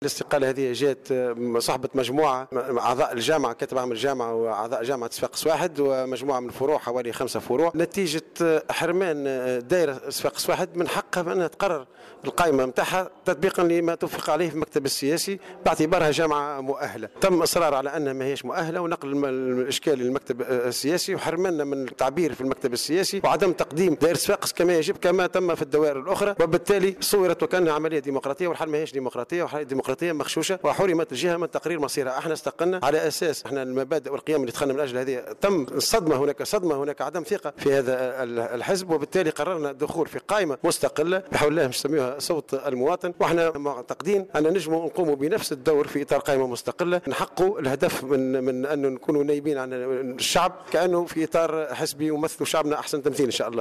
تصريح لمراسل جوهرة "اف ام"